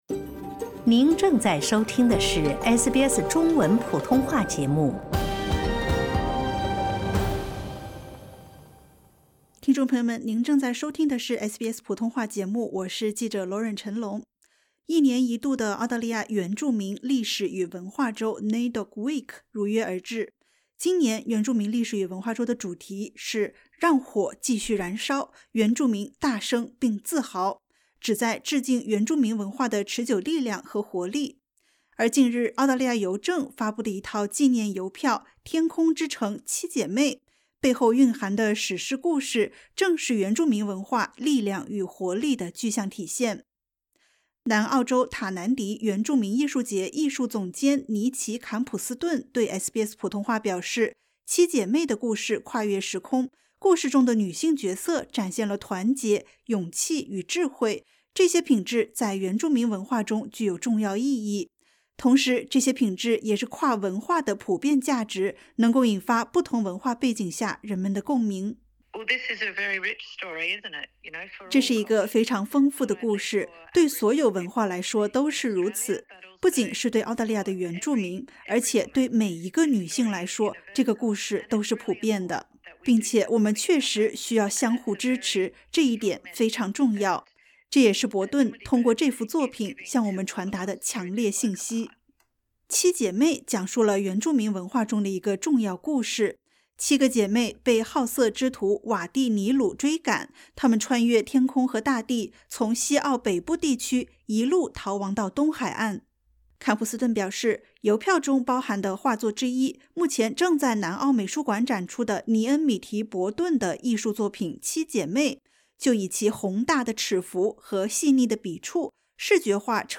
澳大利亚邮政近日发布了《七姐妹》纪念邮票，讲述了原住民女性的团结史诗故事，通过艺术作品传播原住民文化，鼓励非原住民社区了解原住民艺术的独特魅力。点击 ▶ 收听完整采访。